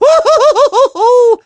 File originally 00075 (0x01EB).wav Artist Charles Martinet Image help • Image use policy • Media file guidelines Licensing Edit Fair use sound clip This is a sound clip from a copyrighted work.
Mario_(blown_away)_-_Mario_Party_6.oga